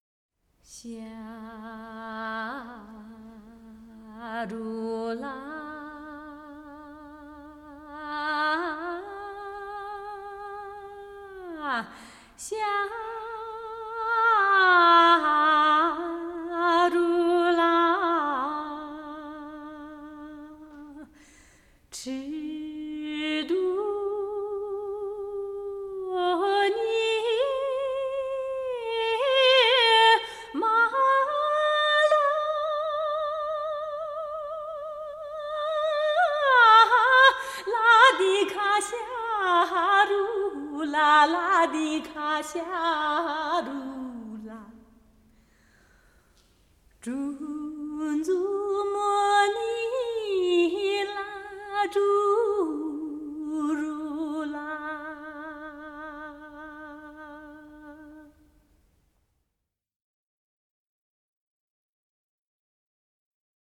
少数民族音乐系列
29首歌，旋律朴实悦耳，歌声高吭开怀，
充份展现厡野牧民和农村纯朴，直率奔放的民风。
都有嘹亮的歌声，一流的技巧。